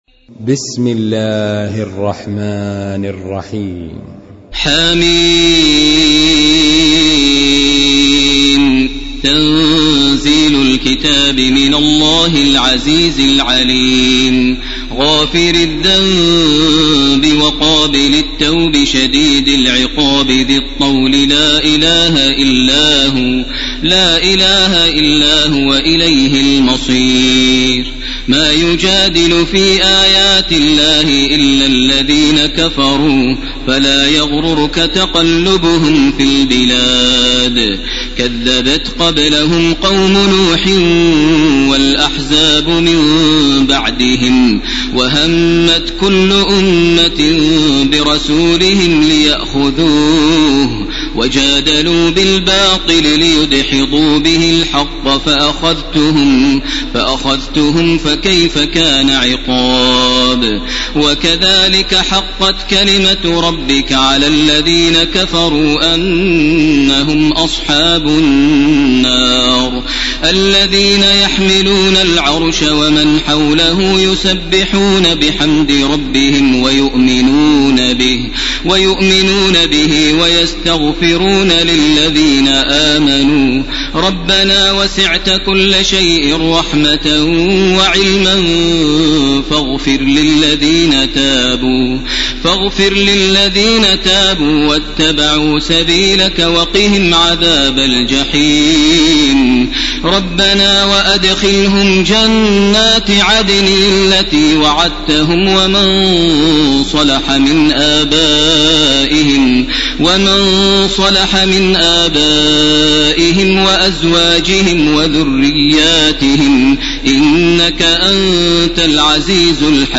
ليلة 23 من رمضان 1431هـ من الآية 01 من سورة غافر وحتى الآية 46 من سورة فصلت. > تراويح ١٤٣١ > التراويح - تلاوات ماهر المعيقلي